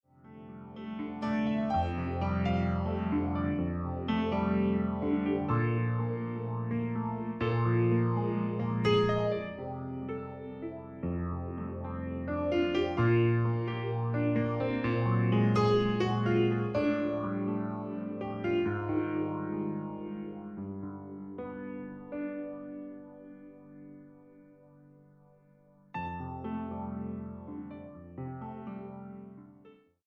Keyboards, Computer
Piano Ballad, Elektro, Kinderlied,
Techno, Rock ...